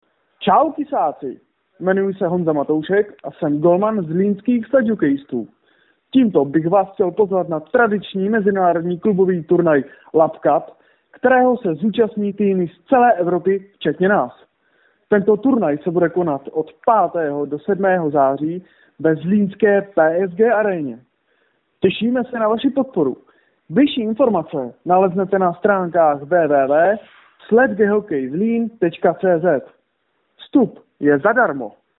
Audio pozvánky na mezinárodní turnaj ve sledge hokeji LAPP CUP ZLÍN 2013. Které namluvili tři hráči zlínského týmu SHK Lapp Zlín. Pro zlínské rádio Kiss Publikum.